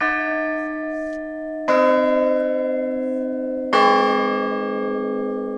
Church Bells
Peal set of Stuckstedes 26", 32", 38" Cast in 1910